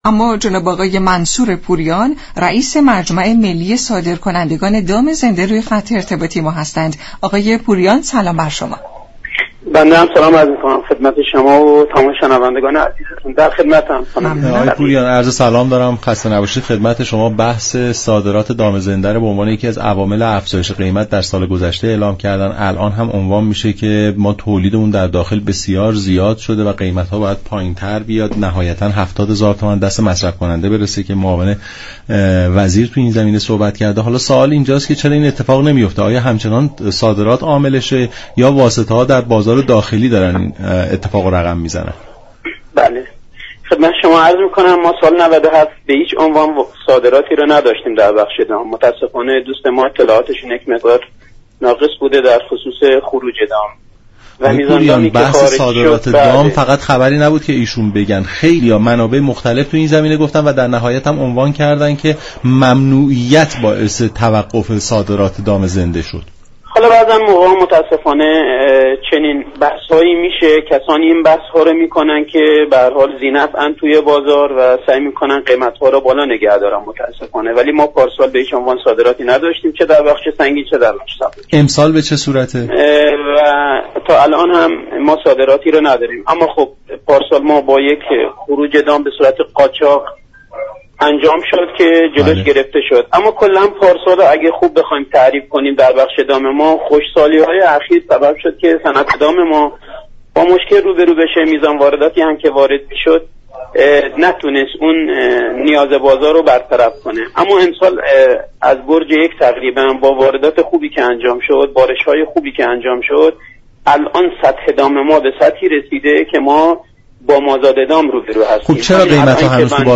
در گفت و گو با برنامه نمودار
برنامه نمودار شنبه تا جهارشنبه هر هفته ساعت 10:20 از رادیو ایران پخش می شود.